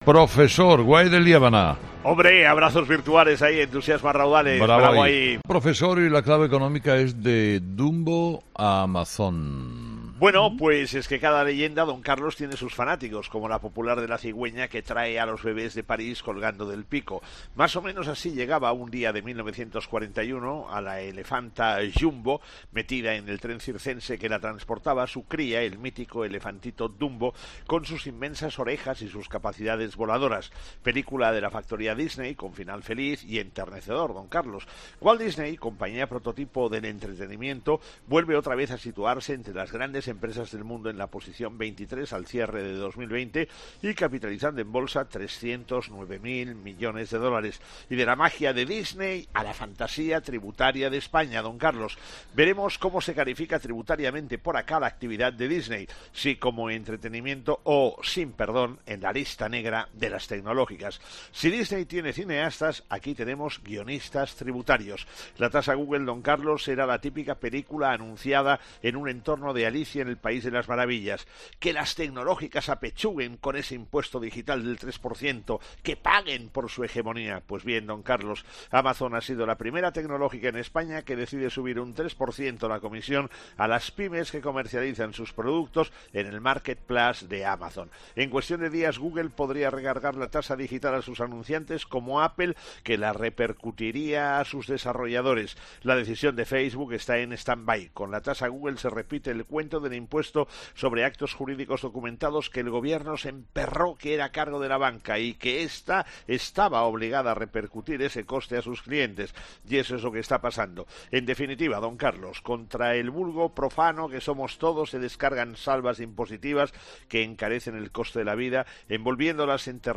La actualidad económica en 'Herrera en COPE' con el profesor Gay de Liébana.